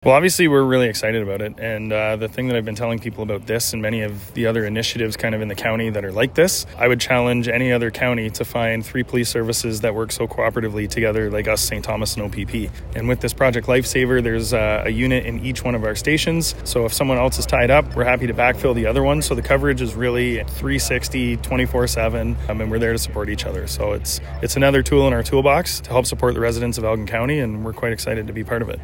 Each of the three local police services now has its own Project Lifesaver equipment on hand, and officers have been trained to respond to calls when someone enrolled in the program goes missing. And as Aylmer Police Chief Kyle Johnstone notes, it’s a lot easier to do when working together.